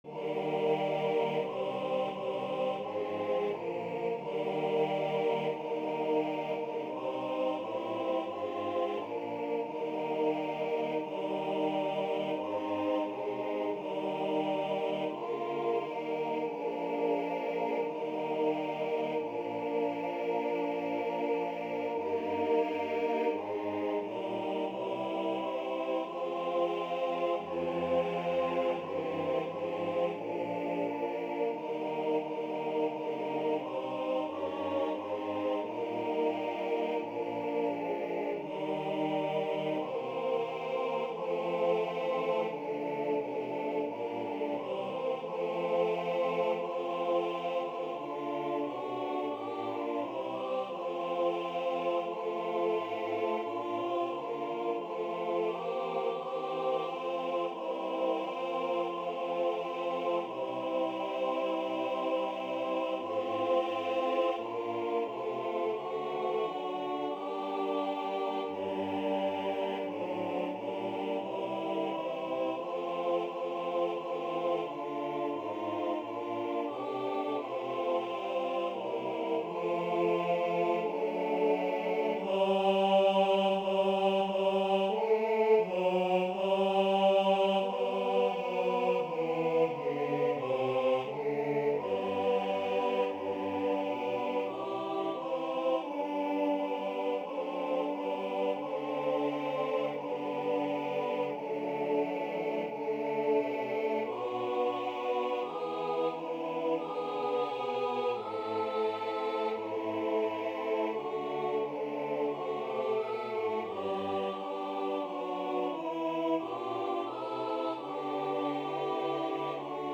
I arranged this for barbershop quartet. There ae three verses, each in a different key.